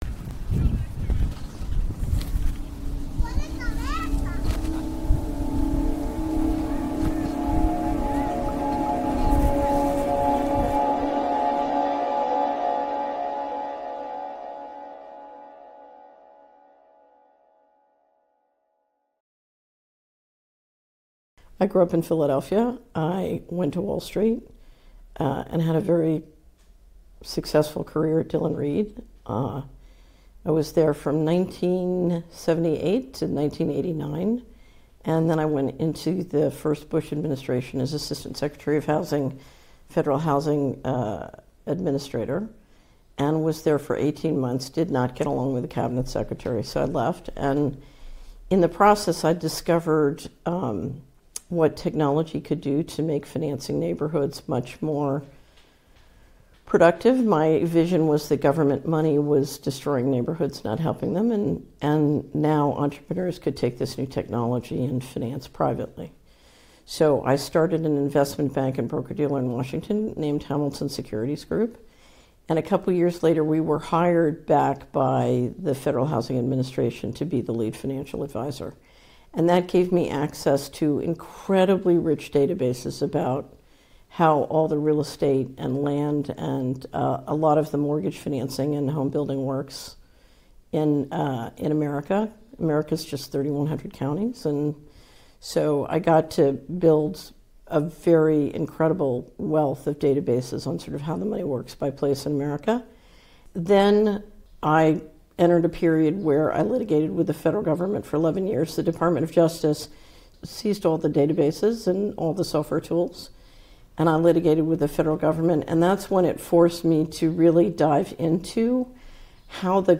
Catherine Austin Fitts - 2nd Full Interview - Planet Lockdown (26 nov 2021)